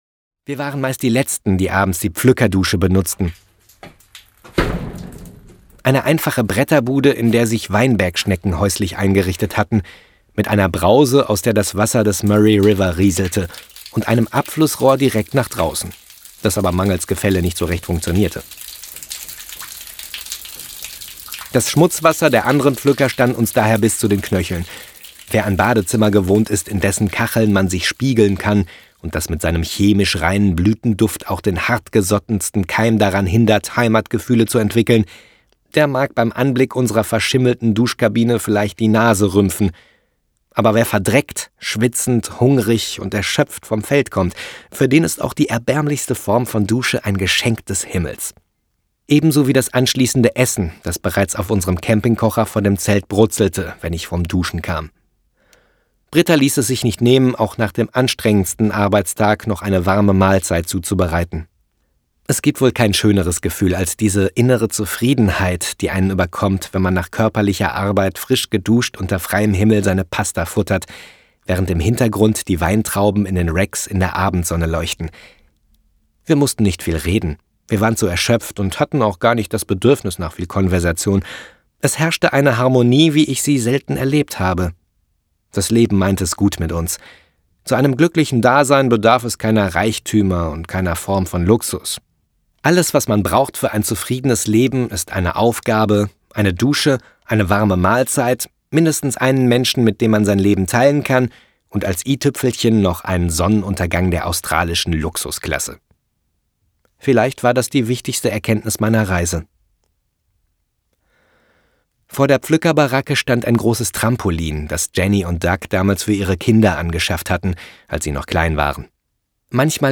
Australien-Hörbuch
Hörbuch „So fühlt sich Freiheit an – Eine Reise durch Australien“, spannende, nachdenkliche und witzige Reiseerlebnisse auf 2 CDs im liebevoll gestalteten Digipack mit 8-seitigem Booklet, handgemachter Musik sowie Originalgeräuschen aus Australien.